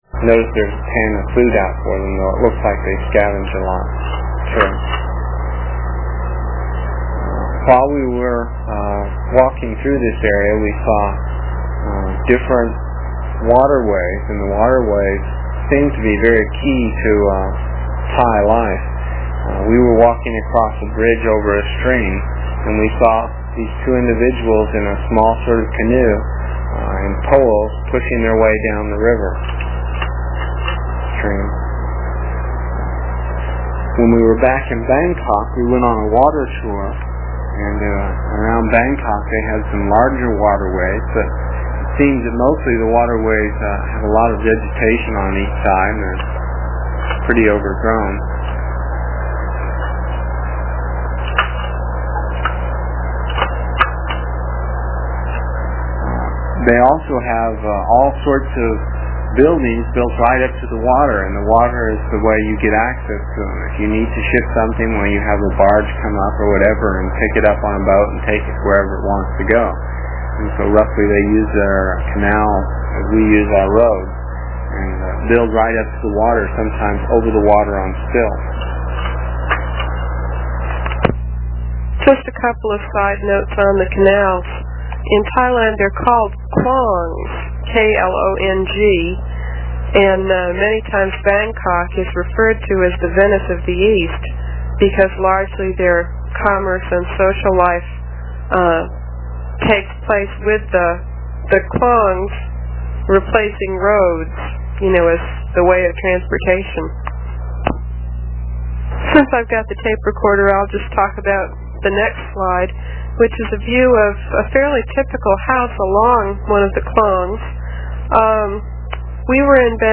Trip to Korea There is a voice description of each slide that you can listen to while looking at the slides. It is from the cassette tapes we made almost thirty years ago. I was pretty long winded (no rehearsals or editting and tapes were cheap) and the section for this page is about eleven minutes and will take about four minutes to download with a dial up connection.